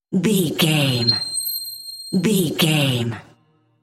Sound Effects
Atonal
funny
magical
mystical